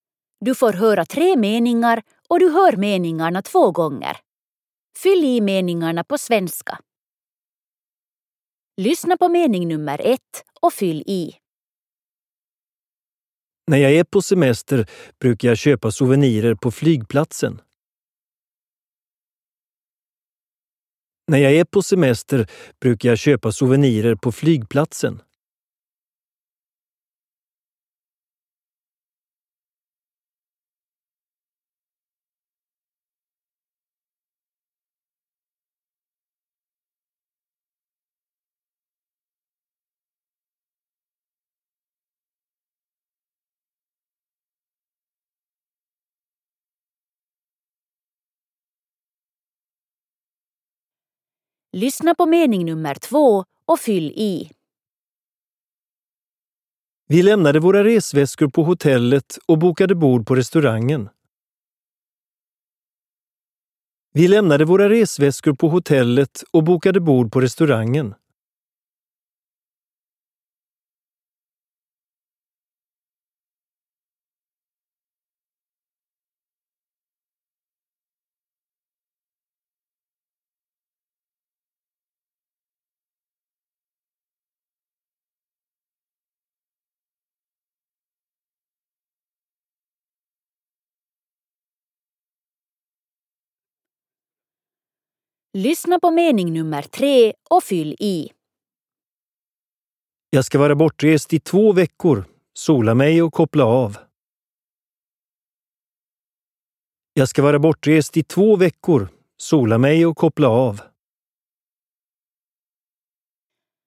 FOKUS resor Sanelu/täydennys 2 Kuulet kolme virkettä, kunkin kaksi kertaa peräkkäin. Täydennä virkkeet ruotsiksi.